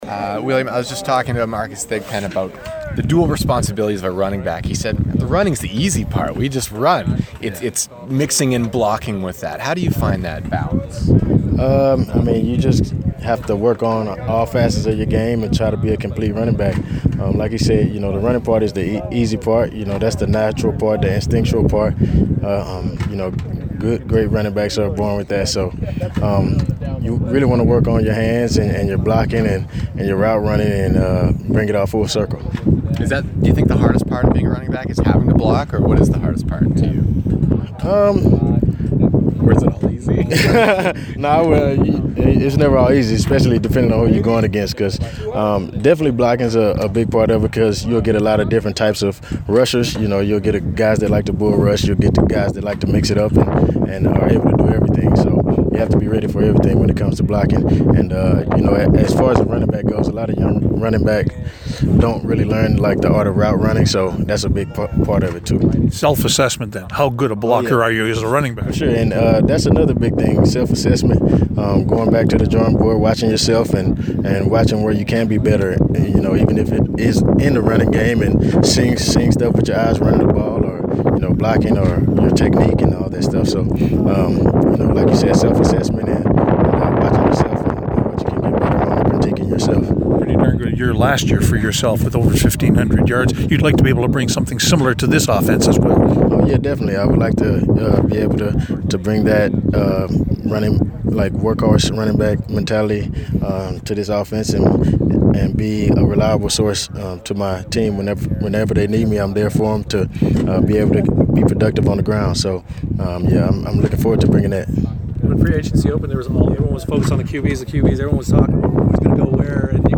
Media Scrum With Former Ottawa